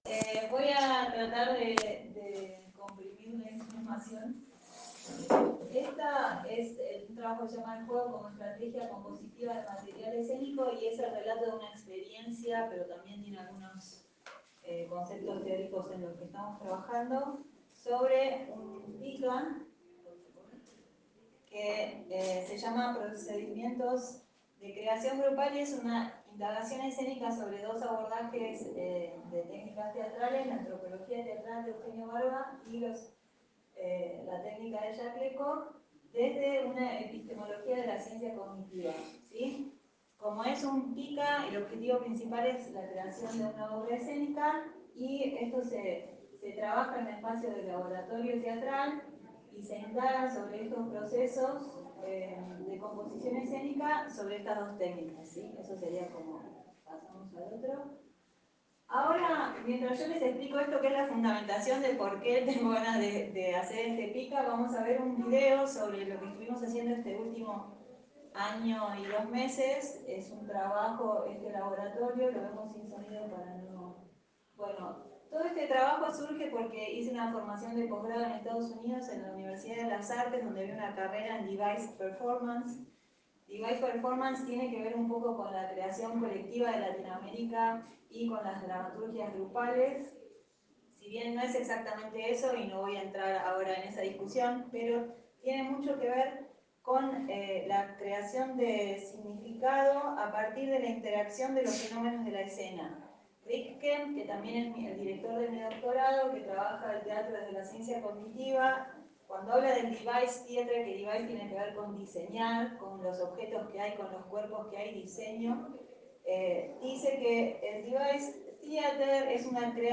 Autdio ponencia .wav